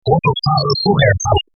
2.2—Here's a second example of the same sentence, this one from Harvey Fletcher, "Some Physical Characteristics of Speech and Music," Journal of the Acoustical Society of America 3:2B (Oct. 1931), 1-25, at page 3, played with the frequency range set to 62.5 to 8000 Hz logarithmic and the time to 1.5 seconds.